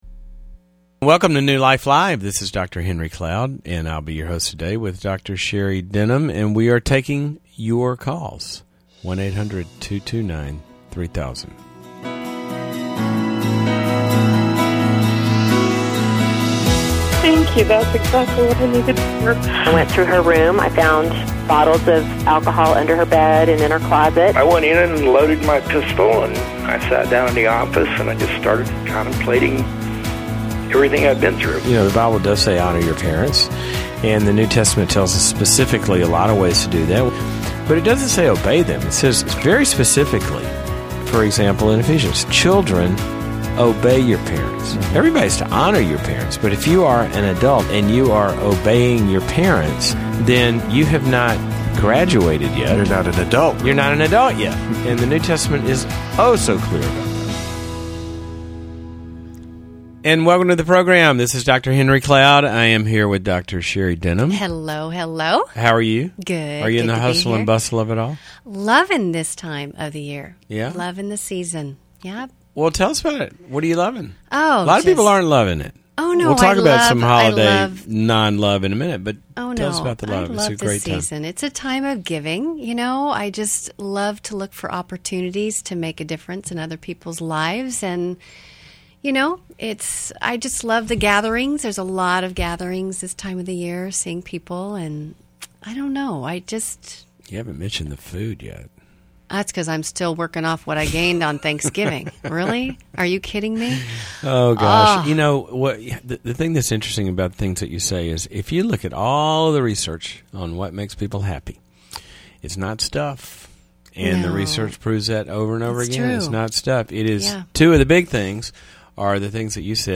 Caller Questions: My husband won’t go to a rehab program unless I move back in.